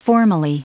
Prononciation du mot formally en anglais (fichier audio)
Prononciation du mot : formally